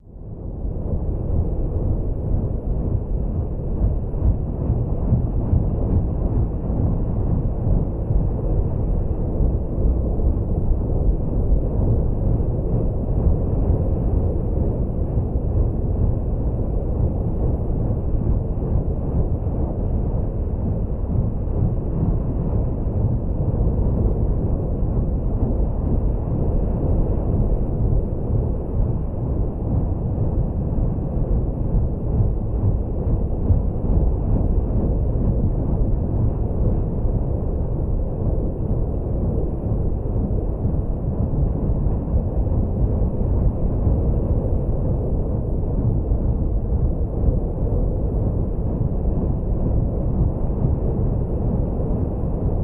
Paddle Boat Underwater